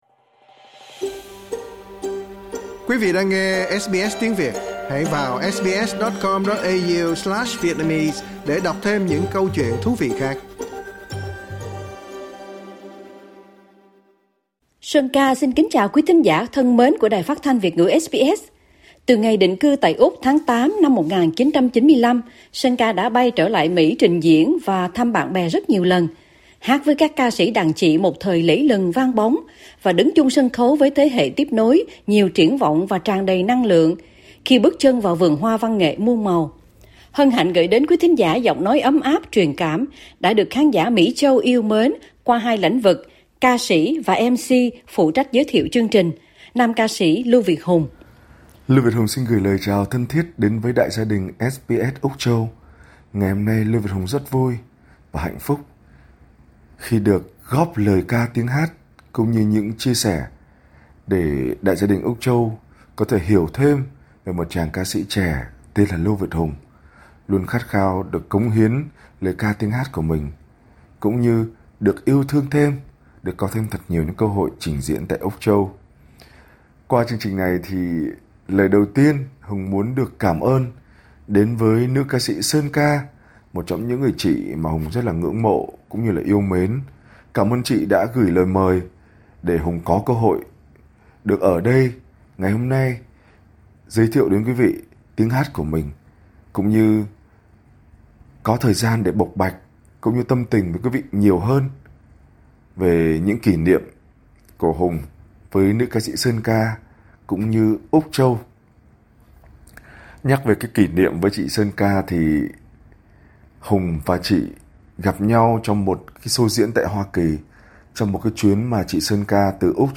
giọng nói ấm áp, truyền cảm